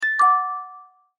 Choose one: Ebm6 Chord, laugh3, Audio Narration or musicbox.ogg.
musicbox.ogg